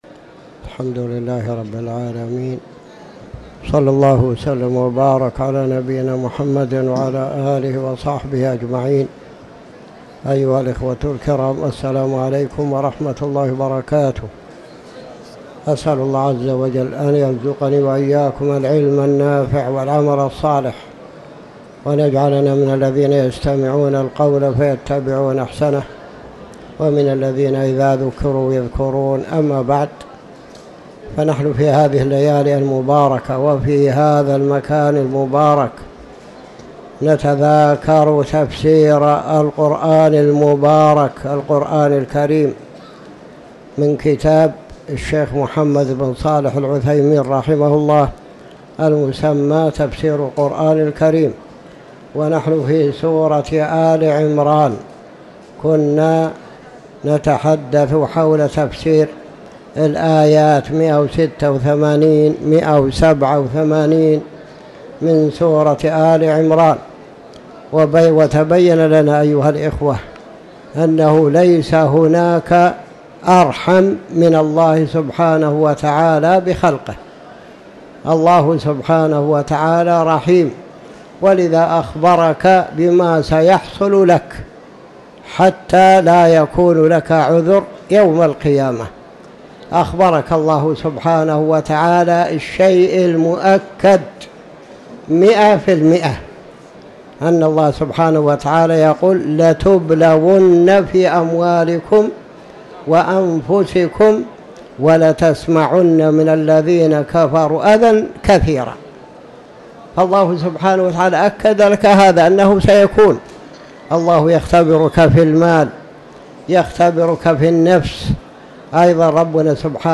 تاريخ النشر ١٧ رجب ١٤٤٠ هـ المكان: المسجد الحرام الشيخ